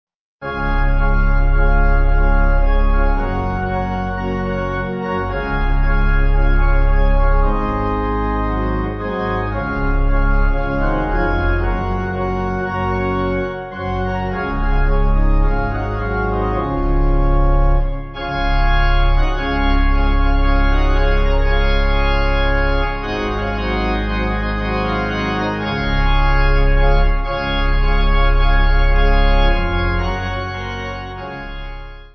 (CM)   4/Db